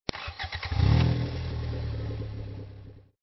EngineStart.WAV